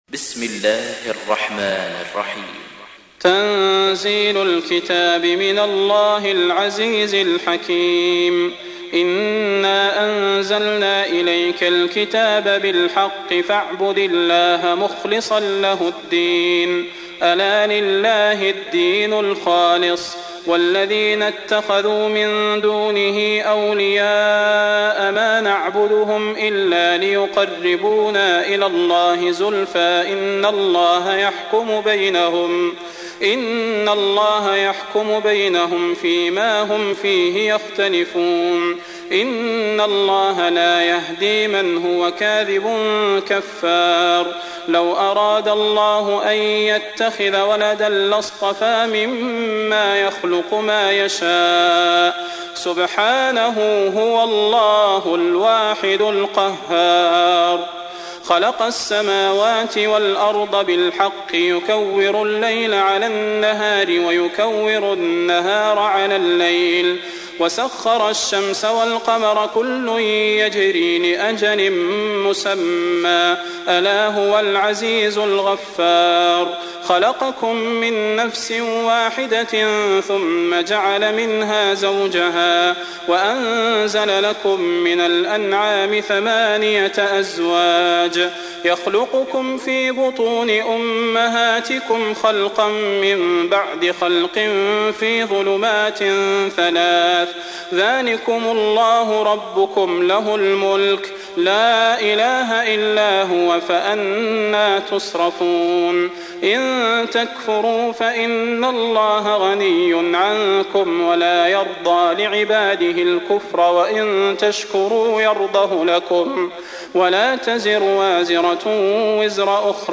تحميل : 39. سورة الزمر / القارئ صلاح البدير / القرآن الكريم / موقع يا حسين